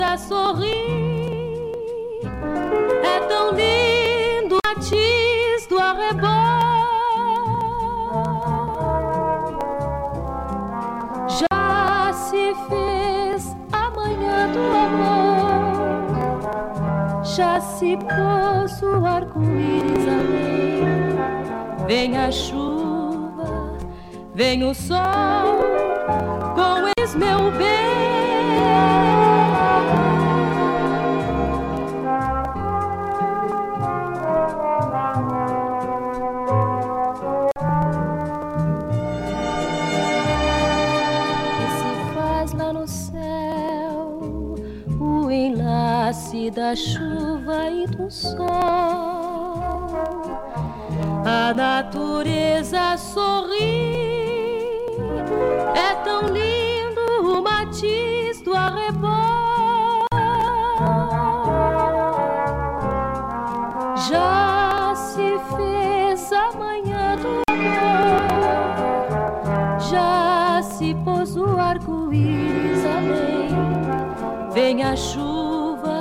fresh and extremely ductile voice
sophisticated Jazz arrangements